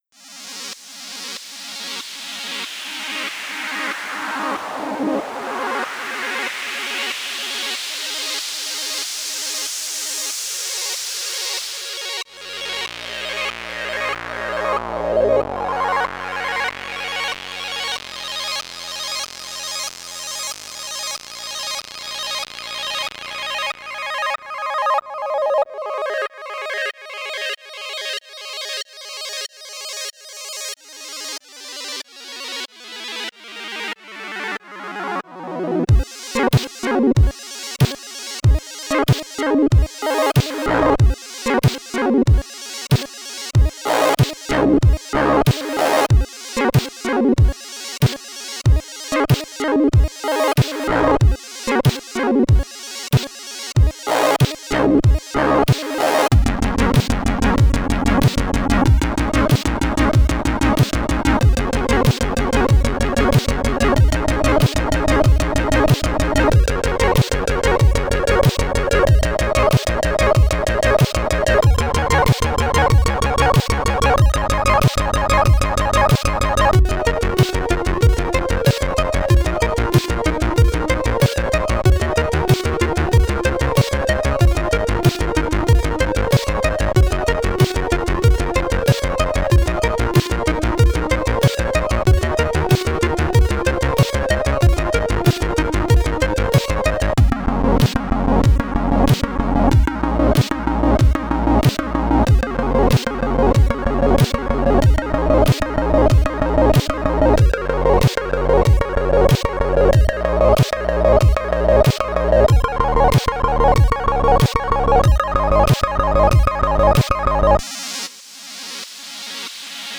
Retrowave song
now it has a tasty intro part in it as well.